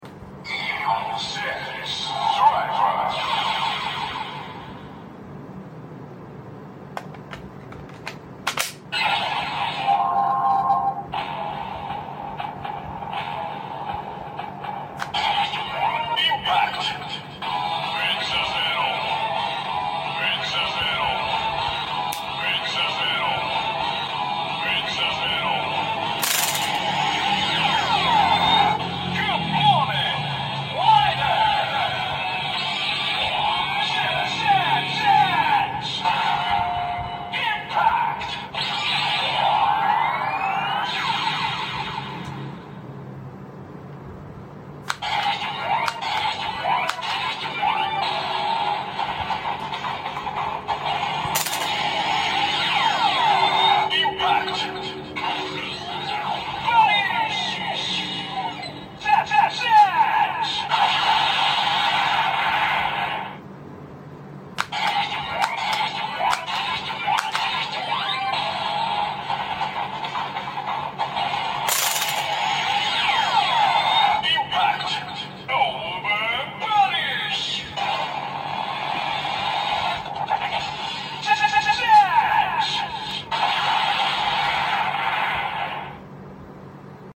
DX Zeztz Driver transformation and sound effects free download
DX Zeztz Driver transformation and finisher sound
THE SUPER LIGHT AND SOUND ARE JUST UMPH!